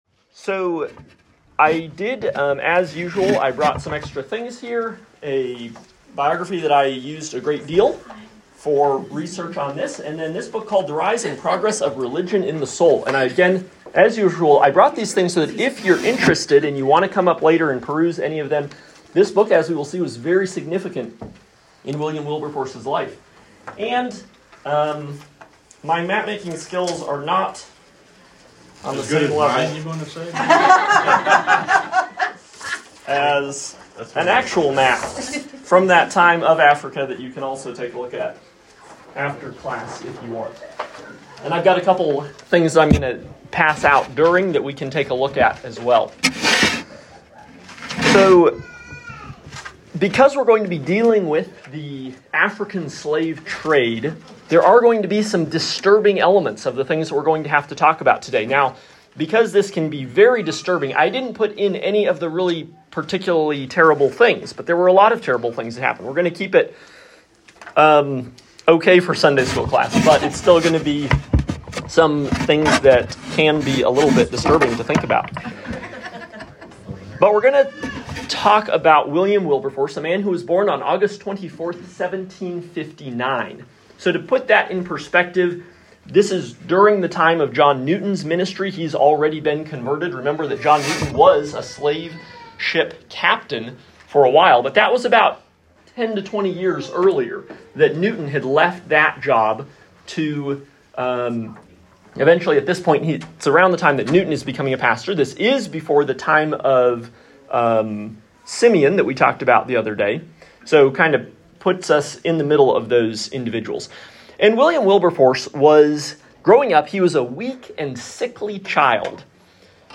Lecture 6 – William Wilberforce